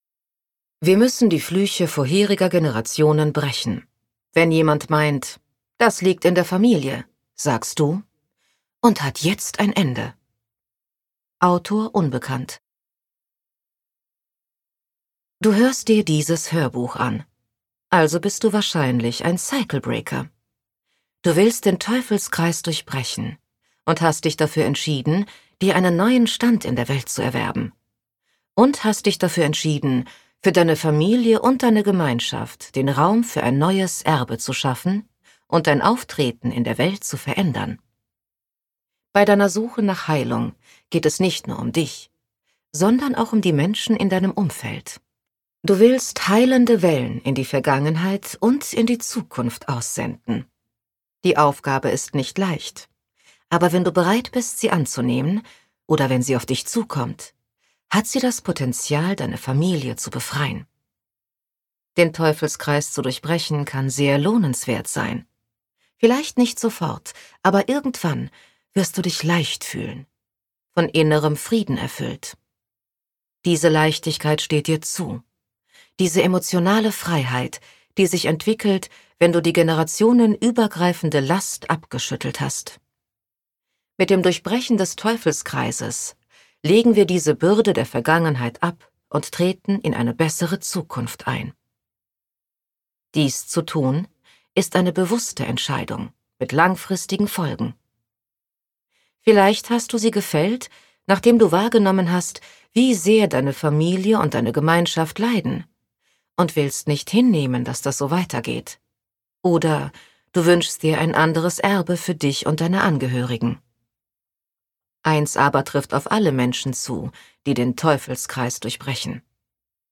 Details zum Hörbuch